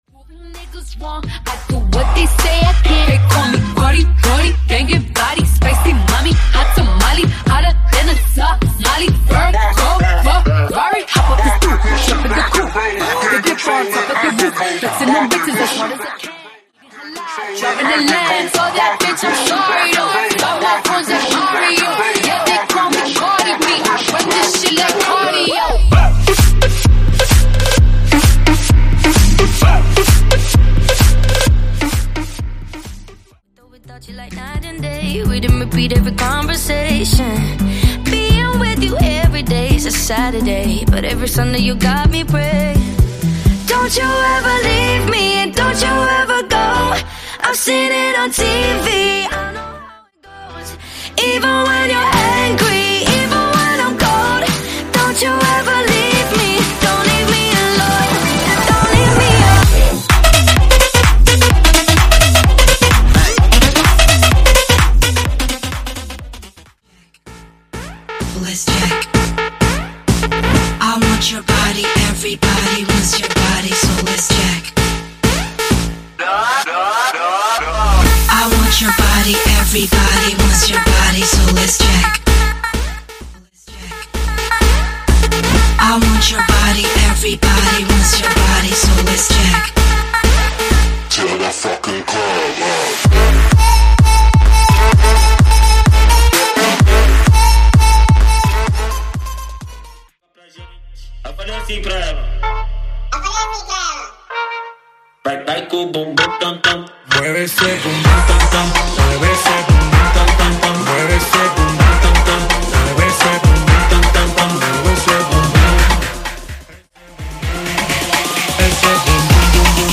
Genre: RE-DRUM
Dirty BPM: 70 Time